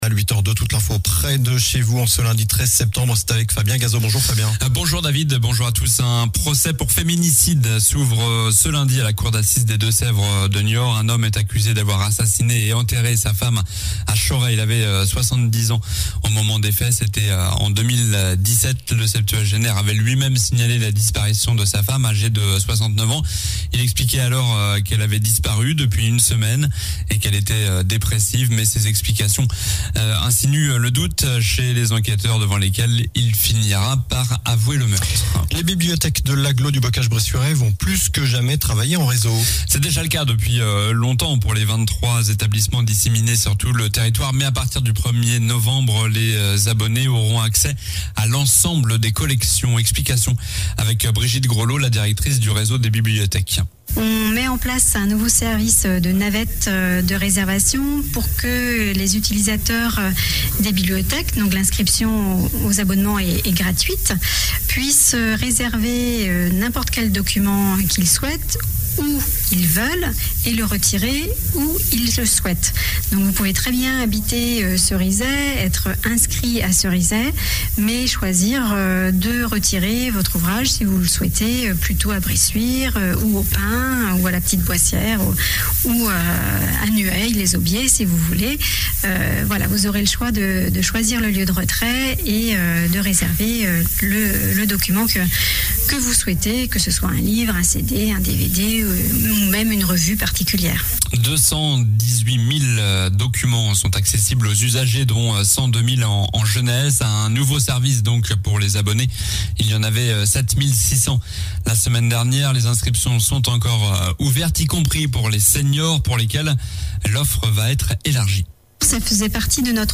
Journal du lundi 13 septembre (matin)